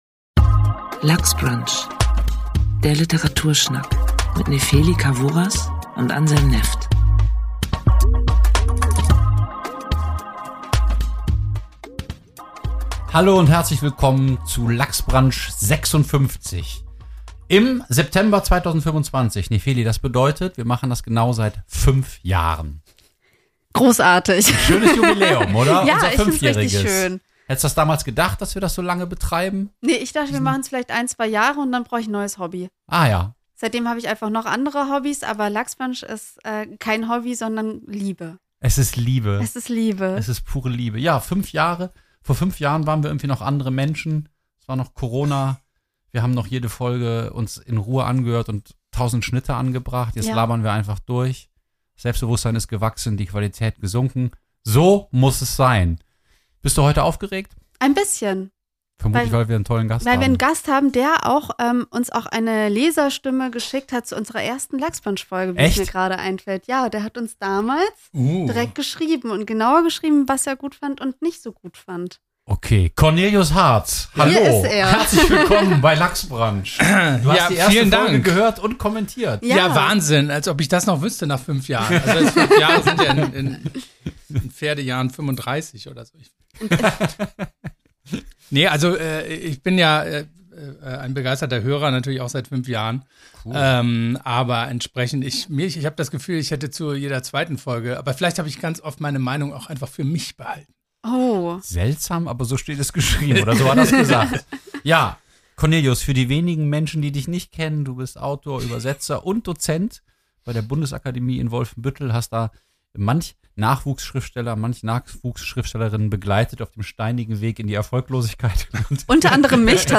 Und wie läuft eigentlich so eine Übersetzung ab? Wir sprechen über Klassenunterschiede und Sexismus, Gesellschaftspanoramen und Figurenpsychologie, starke Frauenfiguren und die Faszination von Sommer-Camps.